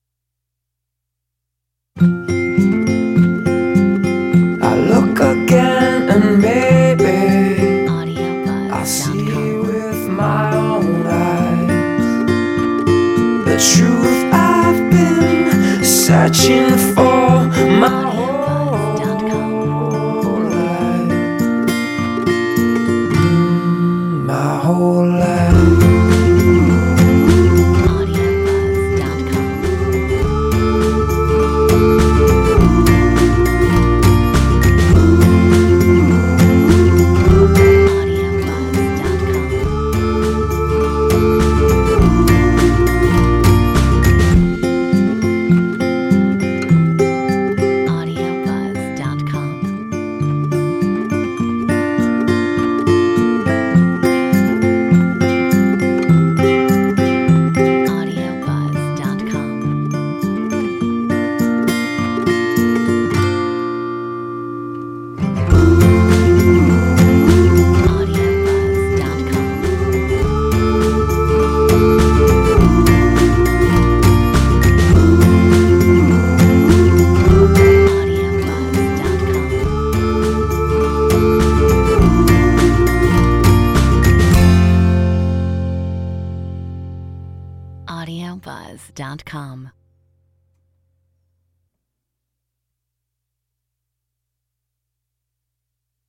Metronome 103